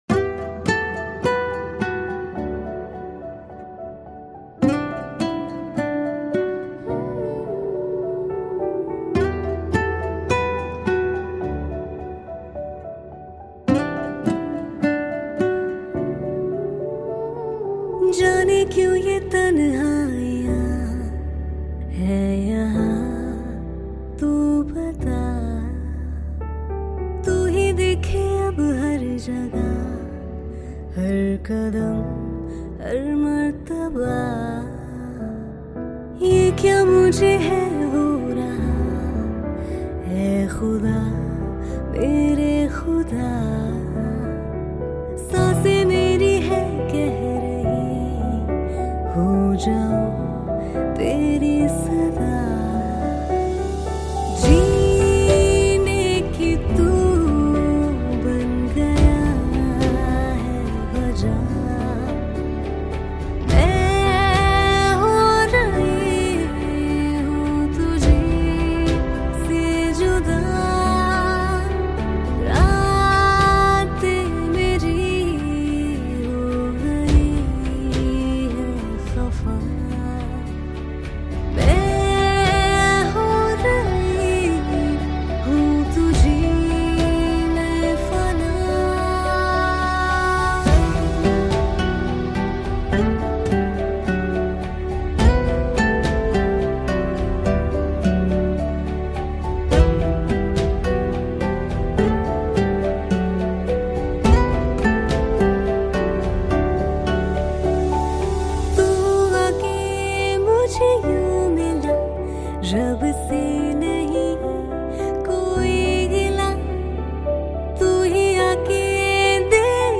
Indie Love Song